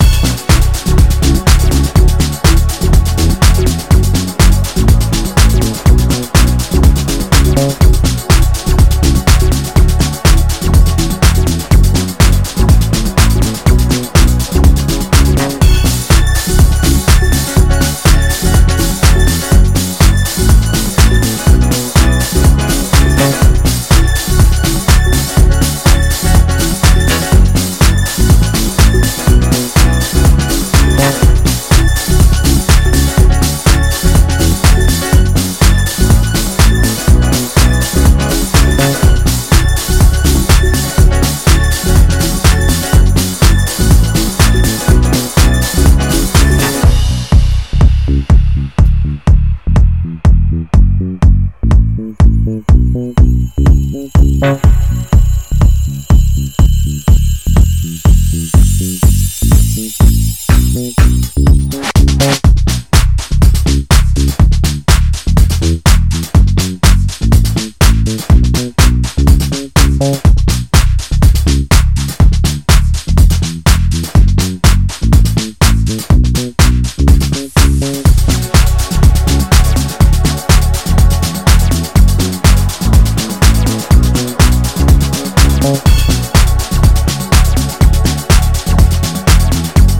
ジャンル(スタイル) DEEP HOUSE / SOULFUL HOUSE